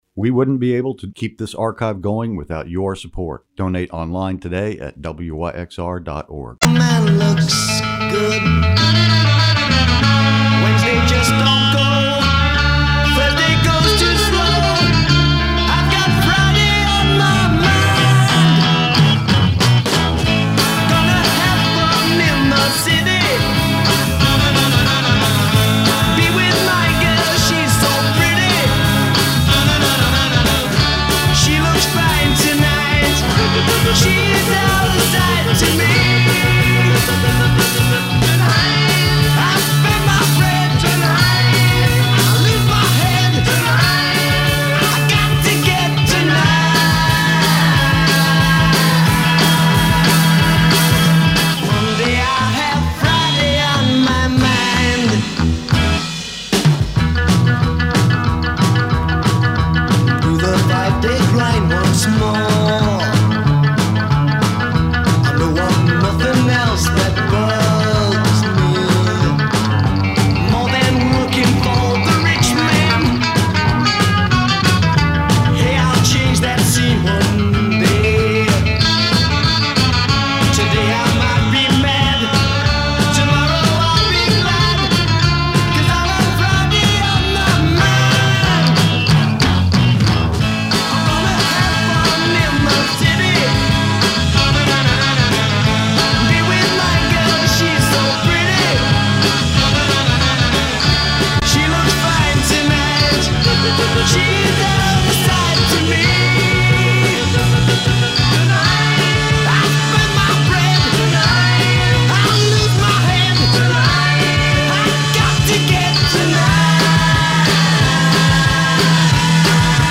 reveals the range, depth and textures of recorded music, direct from his coveted collection and detailed notebook of sound.